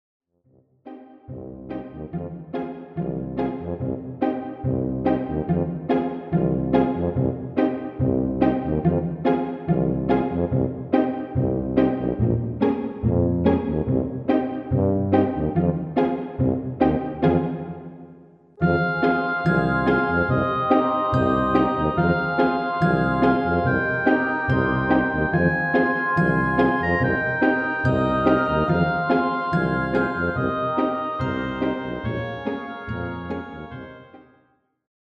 Demo/Koop midifile
Genre: Pop & Rock Internationaal
- Géén vocal harmony tracks